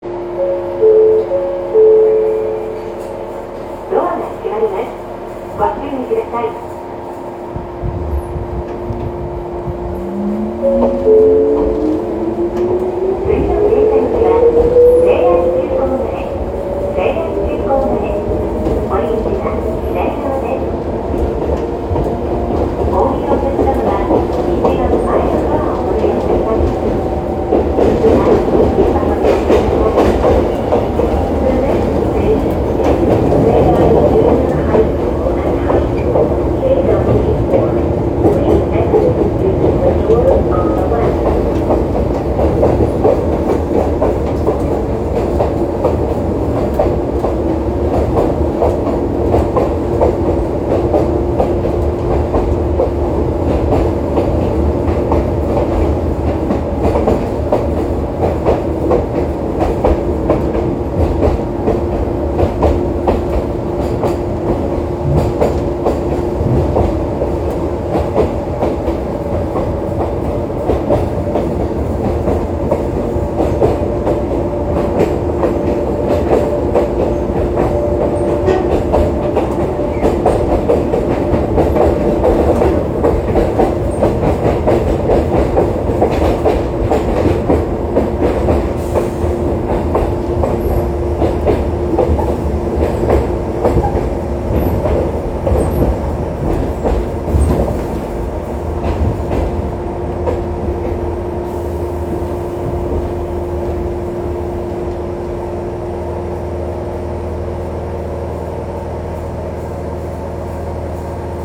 7000ŒnŽž‘ã‚Ì‰¹‚»‚Ì‚Ü‚Ü‚Å‚·Bƒƒ“ƒ}ƒ“‰»‚³‚ê‚Ä‚¢‚é‚±‚Æ‚ªˆá‚¢‚¾‚ÆŒ¾‚¦‚é‚Å‚µ‚å‚¤B‘¬“x‚Ío‚µ‚½‚èo‚³‚È‚©‚Á‚½‚èB‚±‚Ì‹æŠÔ‚Í•ª”ò‚Î‚µ‚Ä‚‚ê‚Ä‚¢‚Ü‚·B®Aƒƒ“ƒ}ƒ“‰^“]‚ÌÛAŽå—v‰wˆÈŠO‚Å‚Í‘O‚ÌŽÔ—¼‚Ìˆê”Ô‘O‚Ì”àˆÈŠO‚ÍŠJ‚©‚È‚¢‚½‚ßAŒã‚ë‚ÌŽÔ—¼‚ÅŽû˜^‚·‚é‚Æ‚«‚ê‚¢‚É˜^‚ê‚é‚©‚à‚µ‚ê‚Ü‚¹‚ñi‚¨‹q‚à‘O‚ÌŽÔ—¼‚ÉW’†‚·‚é‚½‚ßjB